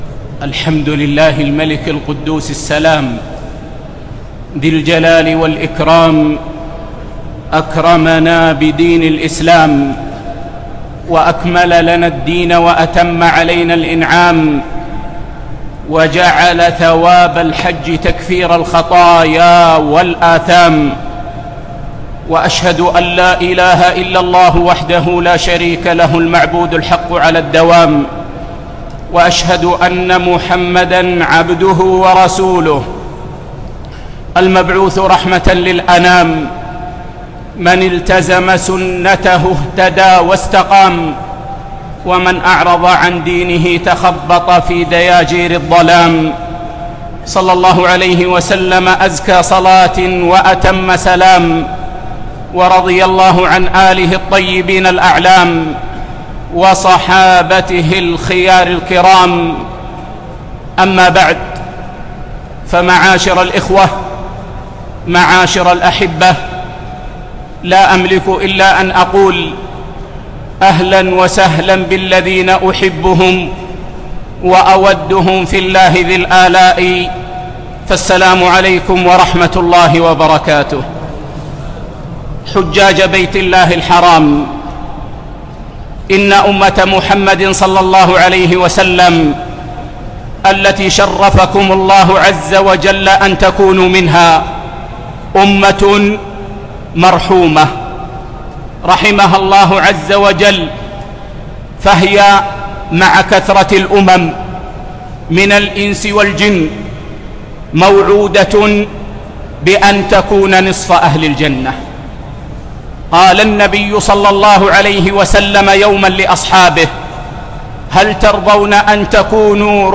يوم الجمعة 10 ذو الحجة 1438 الموافق 1 9 2017 في مسجد الخيف مشعر منى